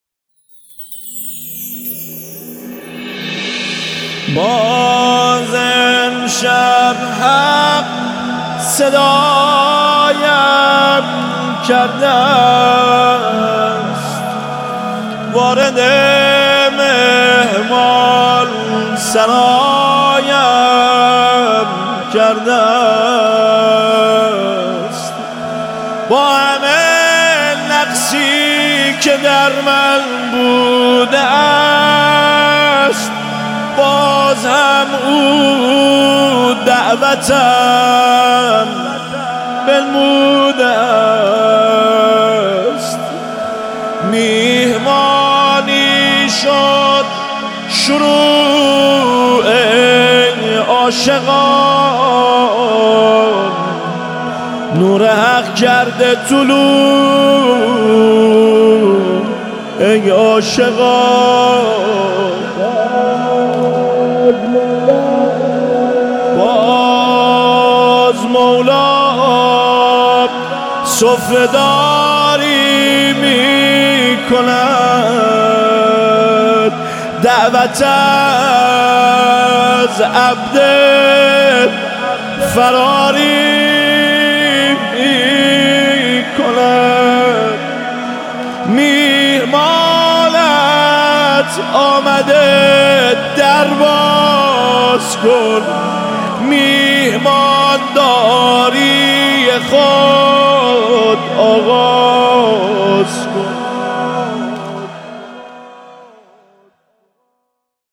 فضای روحانی و معنویت اسلامی را می‌آفریند.
جلوه‌ای از پیوند ایمان، شعر و موسیقی معنوی